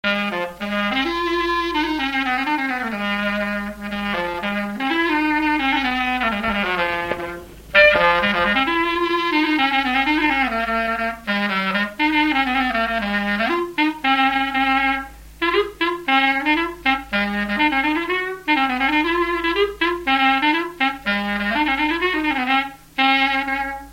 Mémoires et Patrimoines vivants - RaddO est une base de données d'archives iconographiques et sonores.
Résumé instrumental
circonstance : fiançaille, noce
Pièce musicale inédite